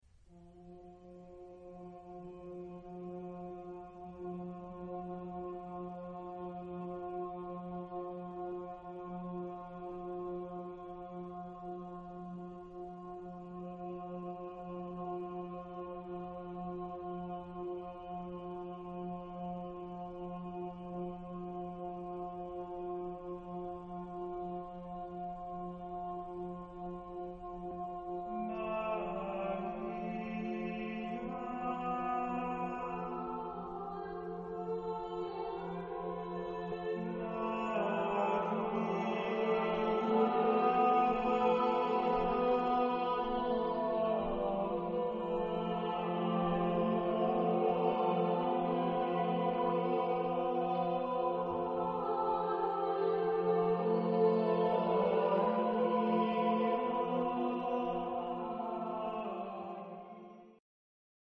Chorgattung: gemischter Chor